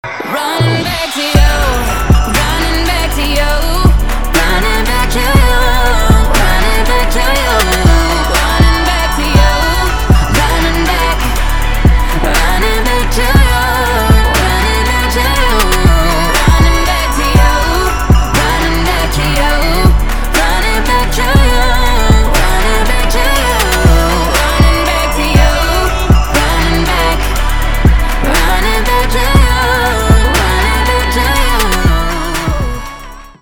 поп
красивые
женский вокал
RnB